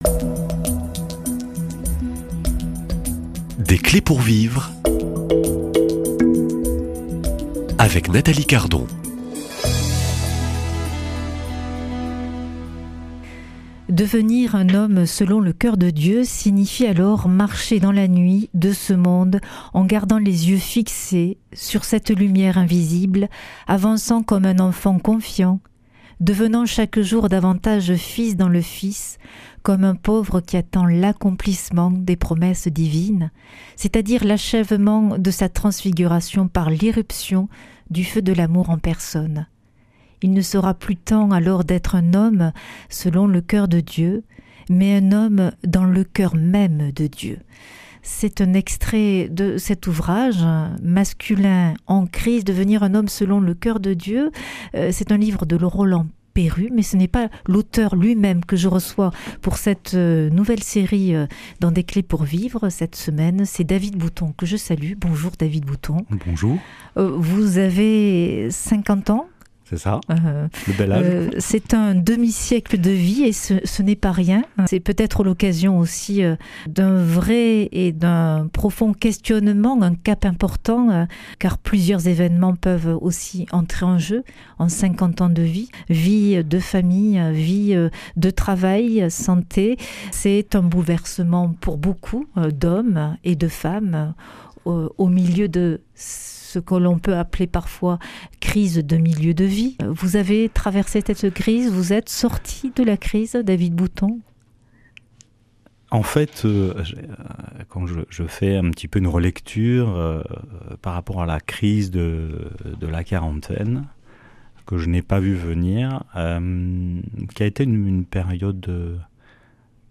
Une émission présentée par